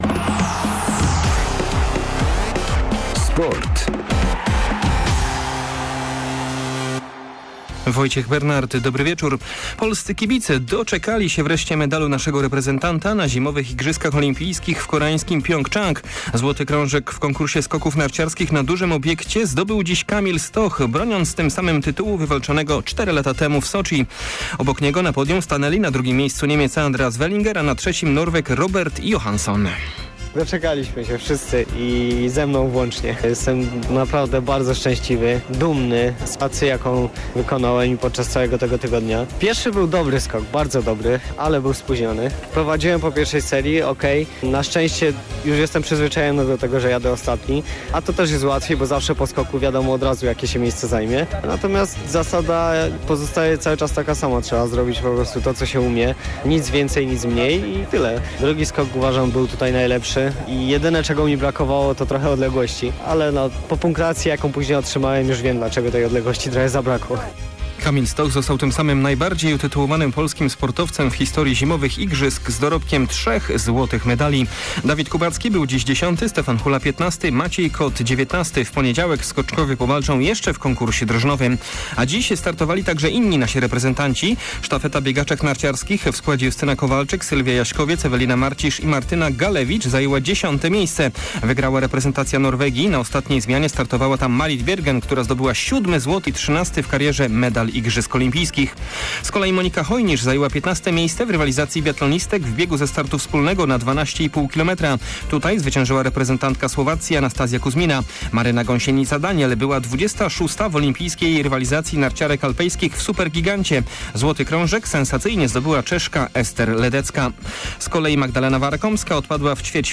17.02 serwis sportowy godz. 19:05
W kolejnym naszym serwisie przede wszystkim o "złotych" skokach Kamila Stocha na Zimowych Igrzyskach Olimpijskich w koreańskim Pjongczangu! Poza tym spora porcja informacji ligowych i zapowiedź niedzielnego meczu piłkarzy Lecha Poznań z Pogonią Szczecin w rozmowie z młodym pomocnikiem Kolejorza - Jakubem Moderem.